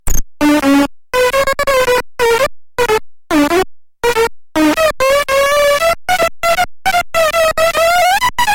电池玩具Kiwi " 机器人说话1
描述：从一个简单的电池玩具中录制的，是用一个猕猴桃代替的音调电阻！
Tag: 弯曲 电子 毛刺 音乐学院-incongrue 电路弯曲 机器人